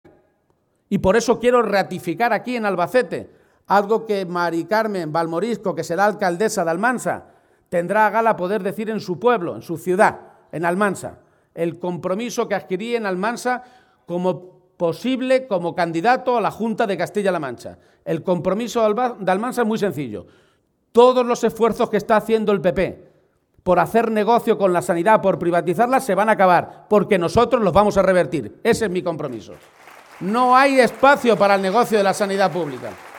García-Page realizó estas declaraciones tras la tradicional comida navideña que celebraron los socialistas albaceteños y a la que asistieron cerca de 400 militantes y simpatizantes del conjunto de la provincia.
Audio García-Page comida PSOE Albacete-2